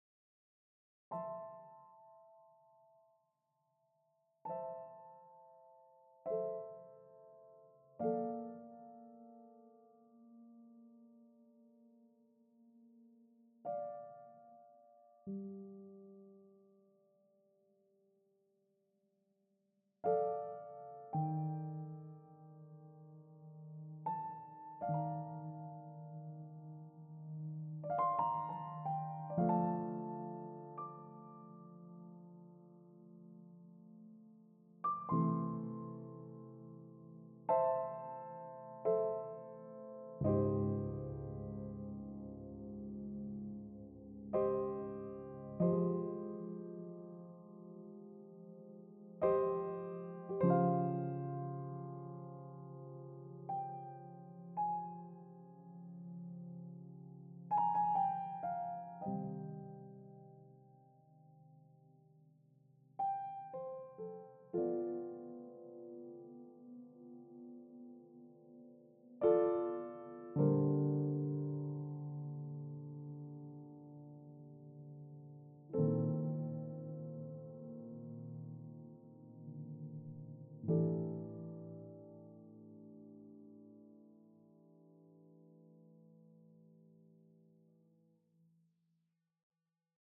Spontaneous autumnal piano improvisation